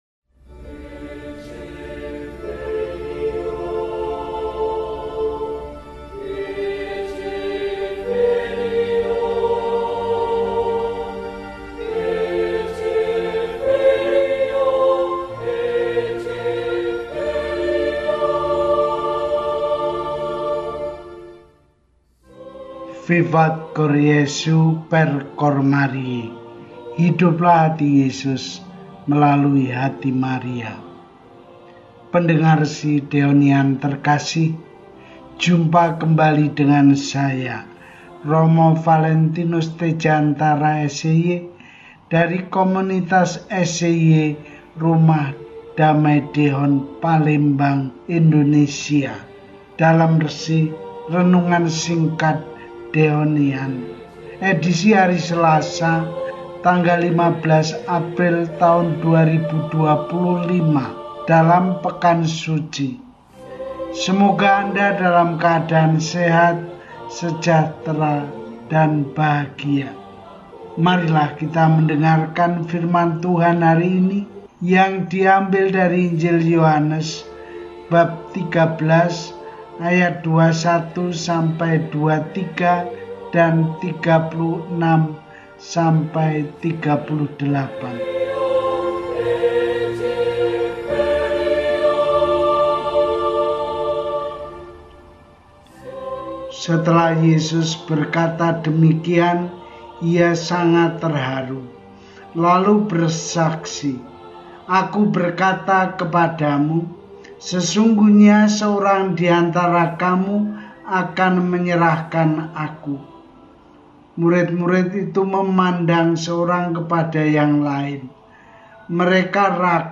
Selasa, 15 April 2025 – Hari Selasa dalam Pekan Suci – RESI (Renungan Singkat) DEHONIAN